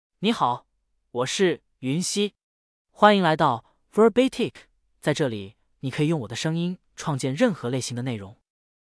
YunxiMale Chinese AI voice
Yunxi is a male AI voice for Chinese (Mandarin, Simplified).
Voice sample
Listen to Yunxi's male Chinese voice.
Male